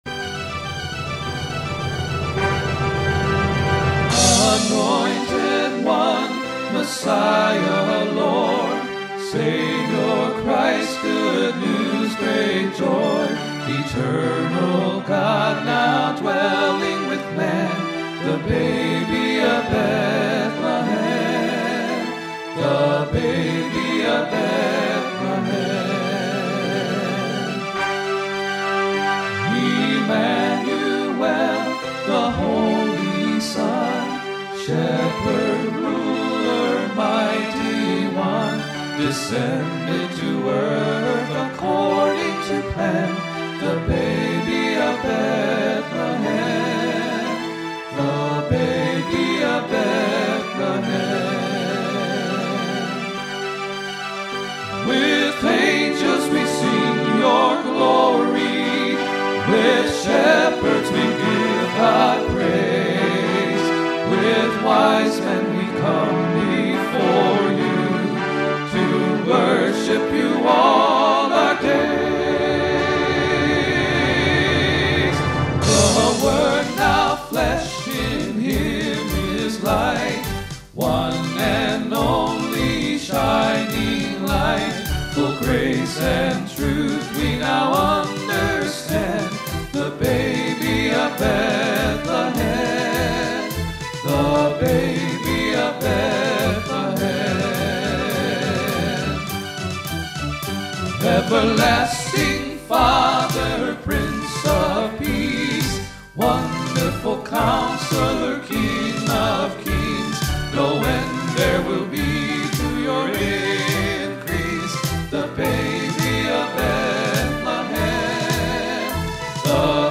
vocal/instrumental recordings